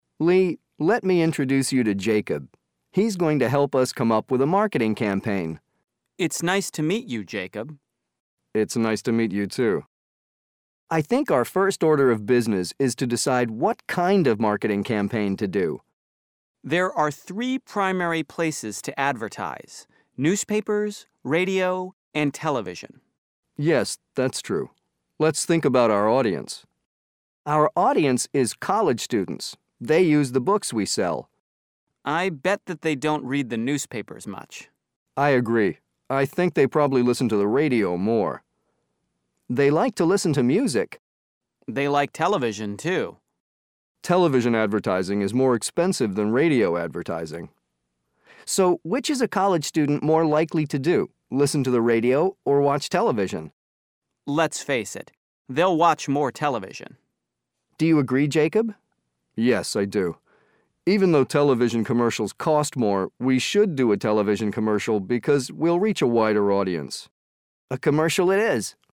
Difficulty: Intermediate (B1)
Conversation